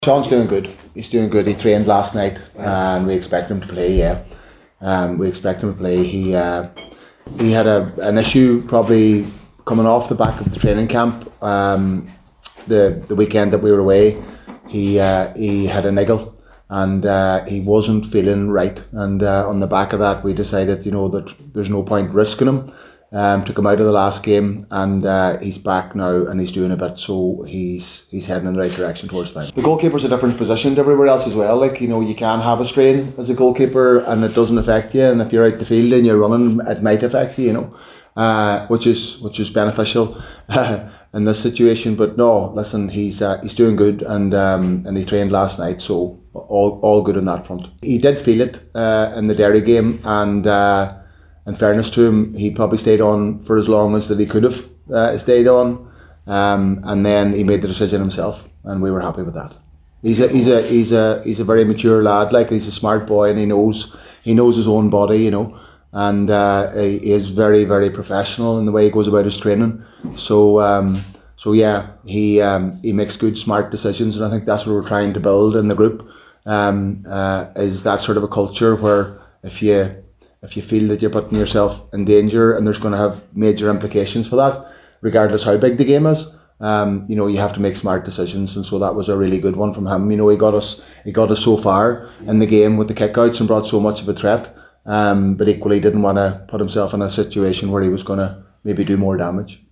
Speaking at a press event last night, McGuinness said the Letterkenny man should be fit for the clash against Armagh…